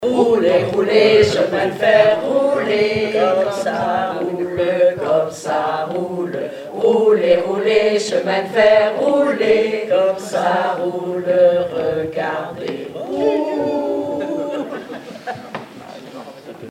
formulette enfantine : amusette
Chansons, formulettes enfantines
Pièce musicale inédite